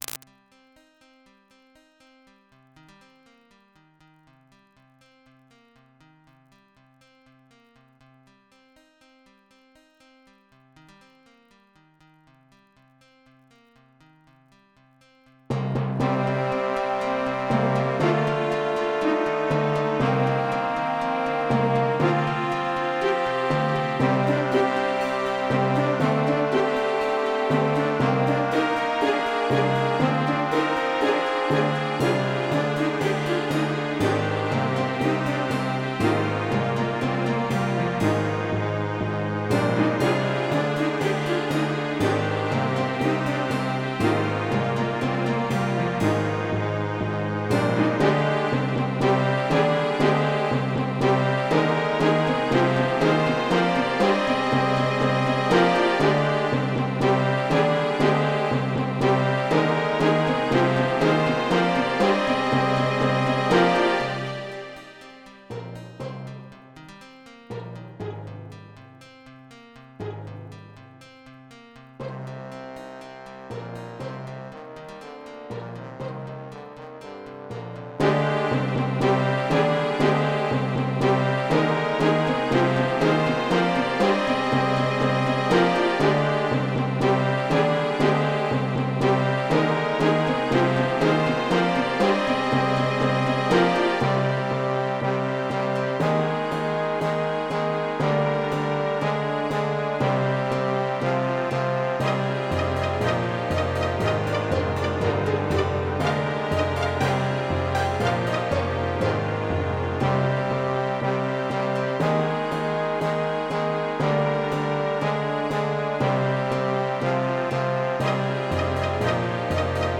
Orchestral, sometimes Techno or 8-bit / 16-bit music.
• Music is loop-able, but also has an ending